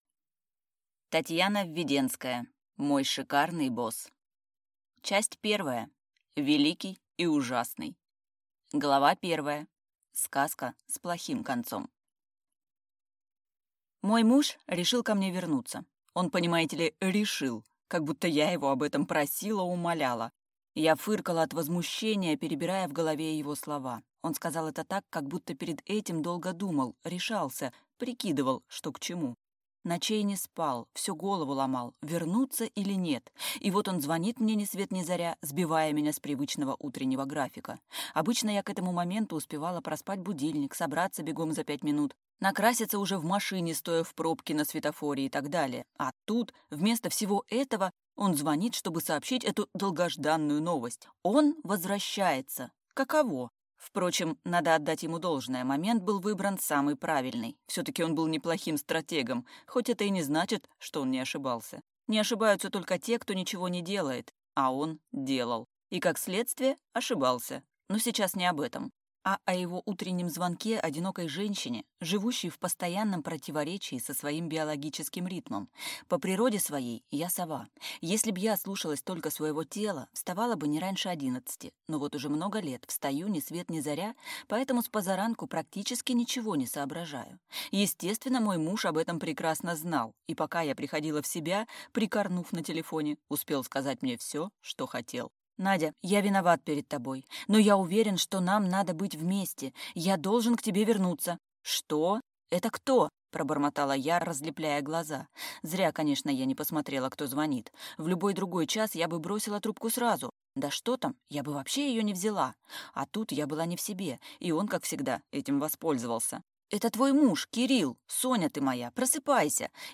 Аудиокнига Мой шикарный босс | Библиотека аудиокниг